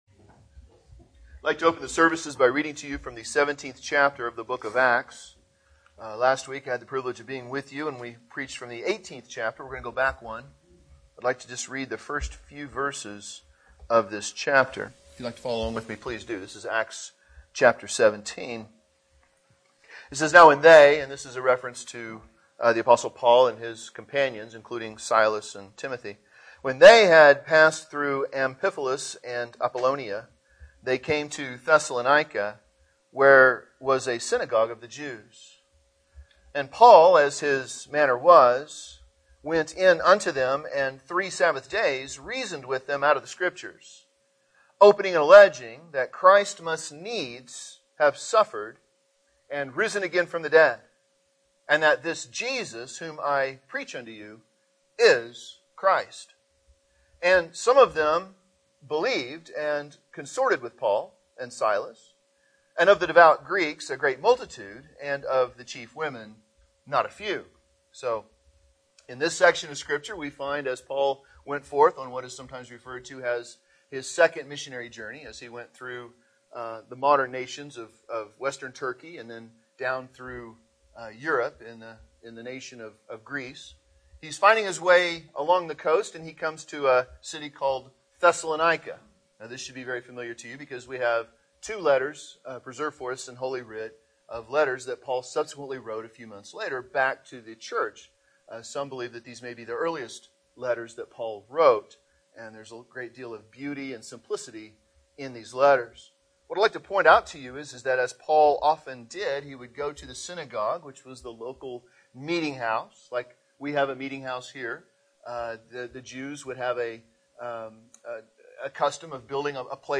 This sermon was recorded at Oxford Primitive Baptist Church Located in Oxford,Kansas